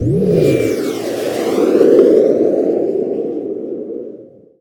general / combat / aircraft / turn.ogg